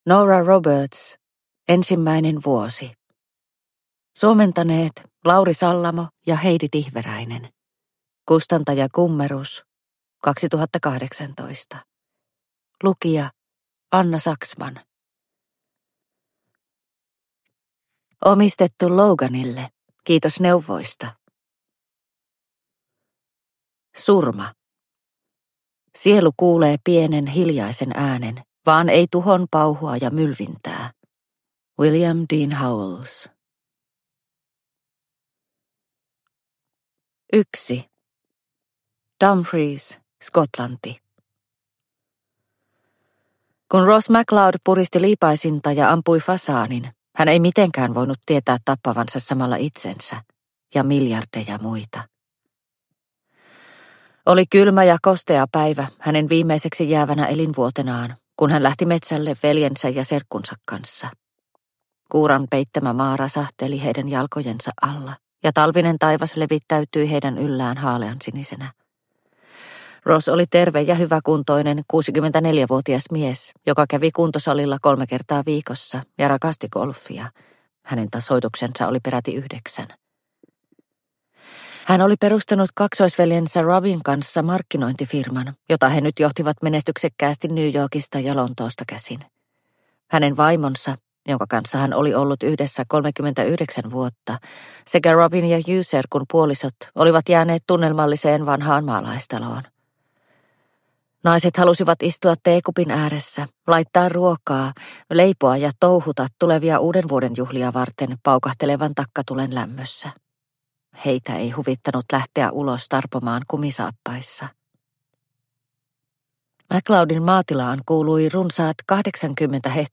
Ensimmäinen vuosi – Ljudbok – Laddas ner